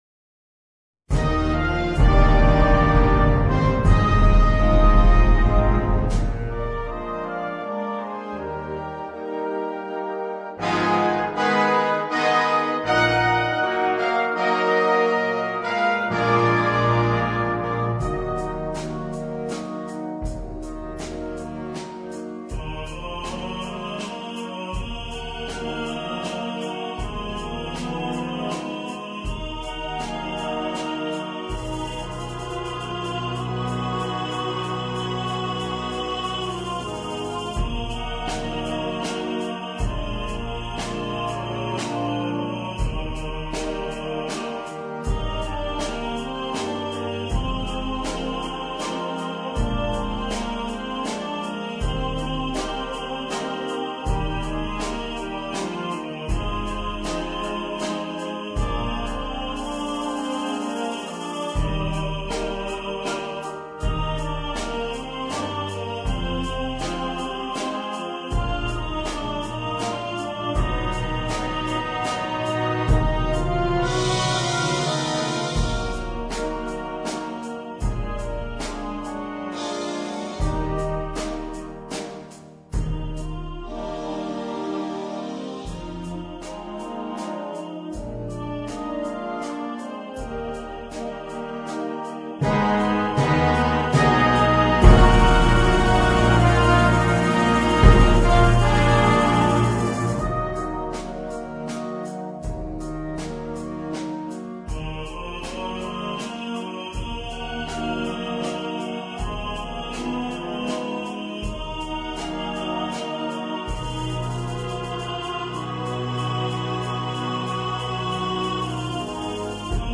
Per tenore e banda
MUSICA PER BANDA